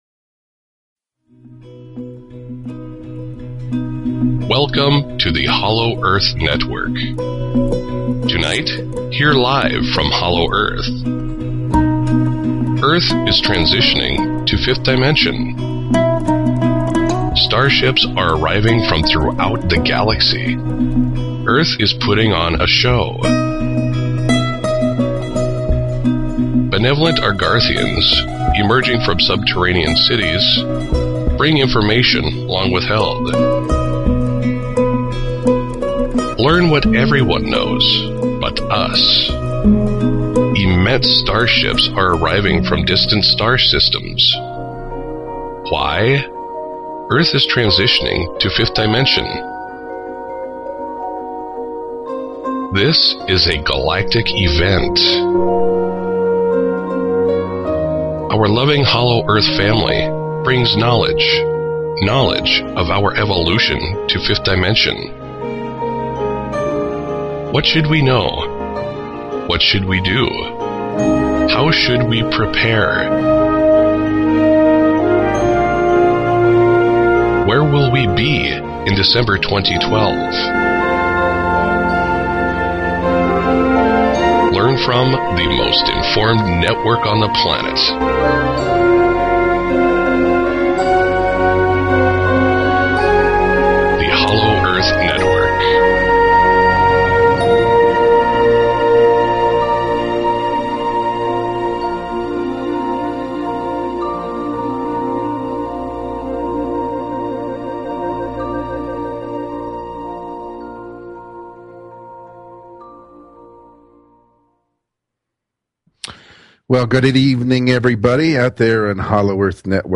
Talk Show Episode, Audio Podcast, Hollow_Earth_Network and Courtesy of BBS Radio on , show guests , about , categorized as